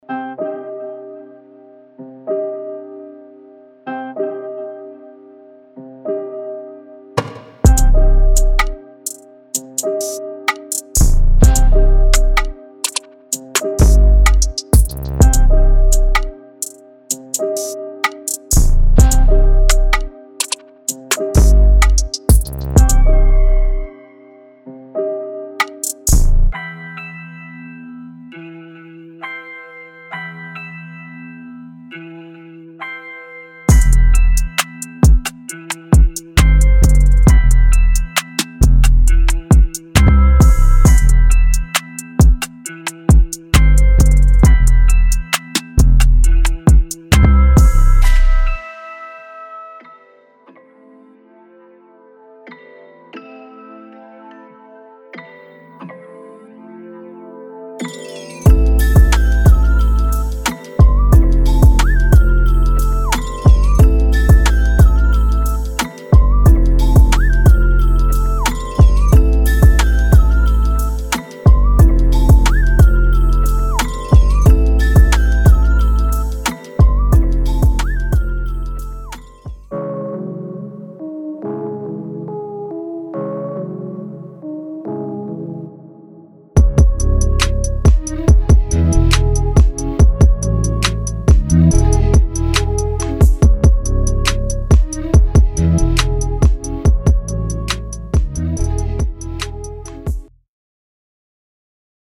Genre:Trap
しかしそれだけではなく、オールドスクールに影響を受けた雰囲気も随所に感じられる内容となっています。
ループとワンショットが豊富に収録されており、最大限の柔軟性を提供します。
デモサウンドはコチラ↓